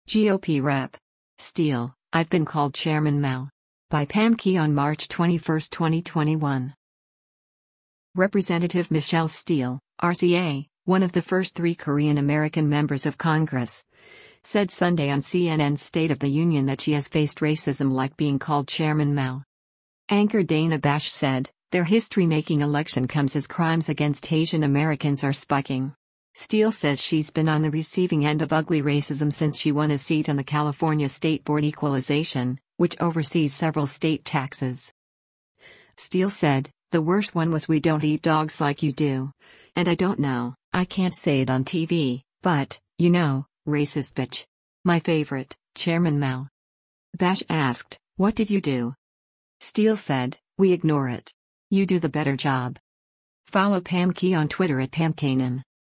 Representative Michelle Steel (R-CA), one of the first three Korean American members of Congress, said Sunday on CNN’s “State of the Union” that she has faced racism like being called “Chairman Mao.”